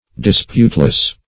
Disputeless \Dis*pute"less\, a.